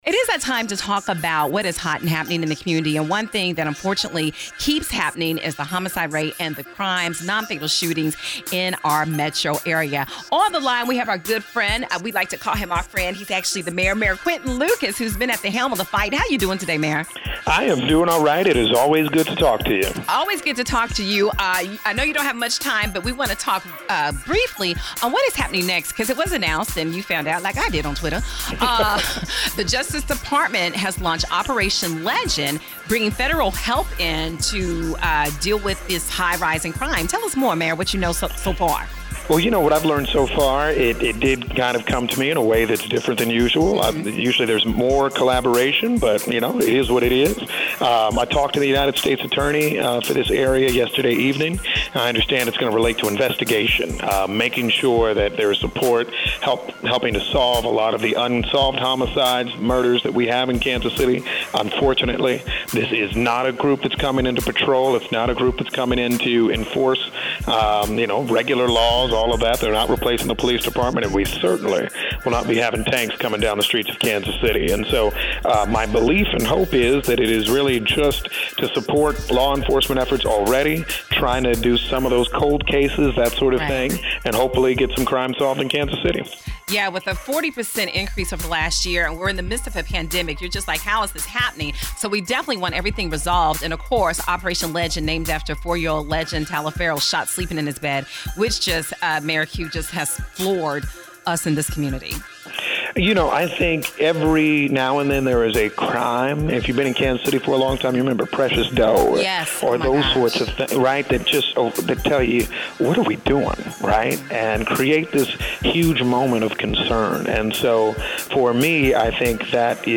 Kansas City has recorded 100 homicides so far in 2020, which is a 40% increase over last year. Mayor Quinton Lucas, who had written Missouri Governor Mike Parson for help, explains what this means for our city.